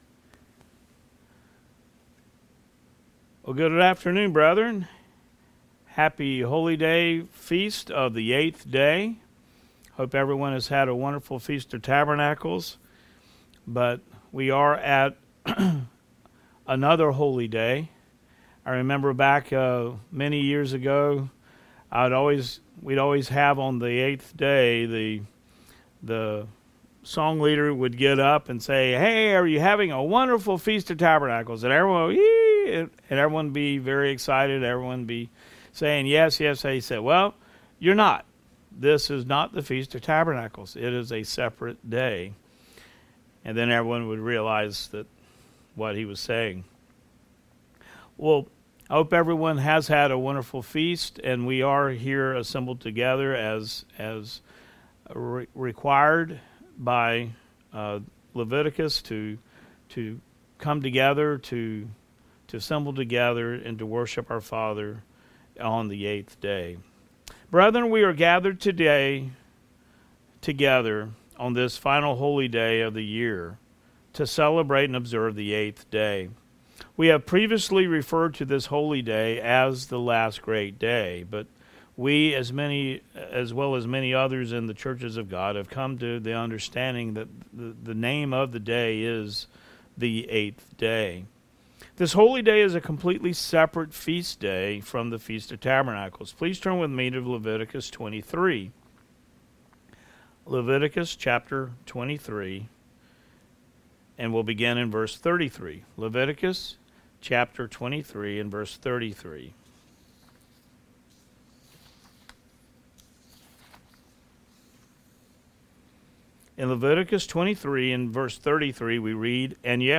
New Sermon | PacificCoG
From Location: "Houston, TX"